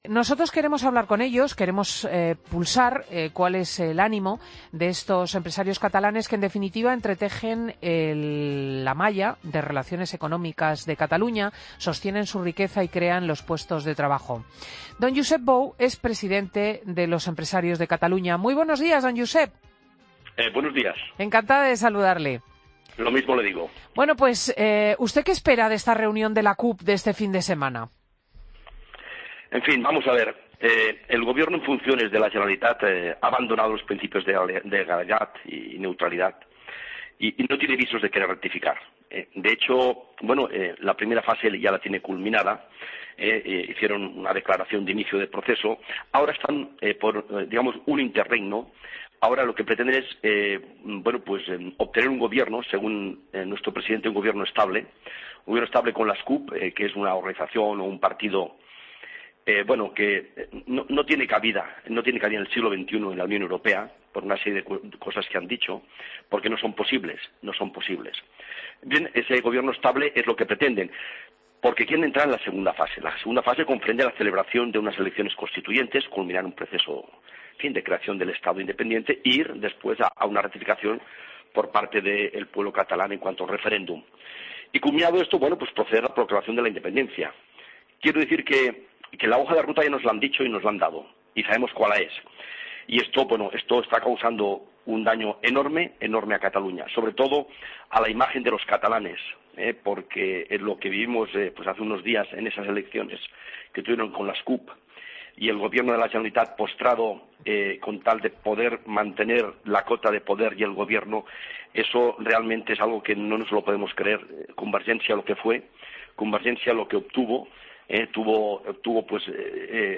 AUDIO: Entrevista